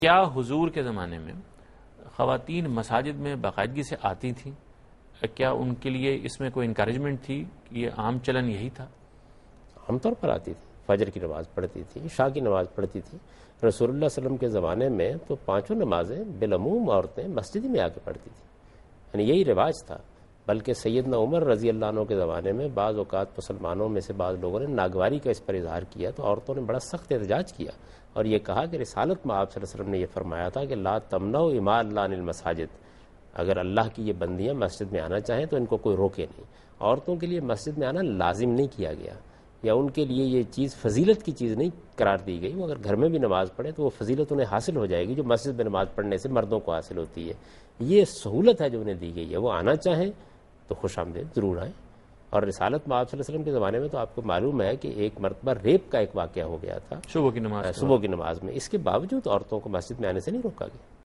دنیا نیوز کے پروگرام دین و دانش میں جاوید احمد غامدی ”عورتوں کا مسجد میں جانا“ سے متعلق ایک سوال کا جواب دے رہے ہیں